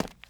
Sounds / sfx / Footsteps / Concrete / Concrete-08.wav
Concrete-08.wav